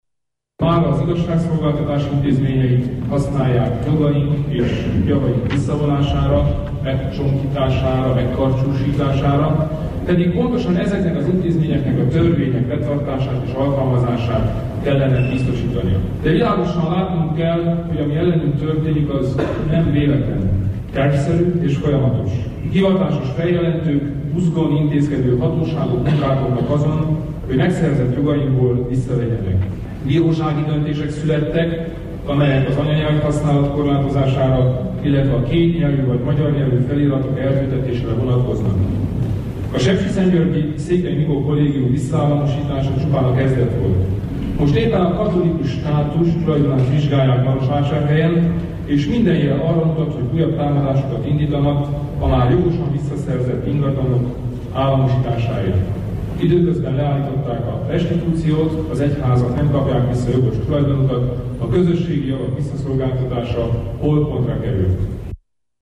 A Romániai Magyar Demokrata Szövetség elnöke a szövetség kisparlamentjének tekintett Szövetségi Képviselők Tanácsa mai marosvásárhelyi ülésén tartott politikai helyzetértékelésében beszélt erről.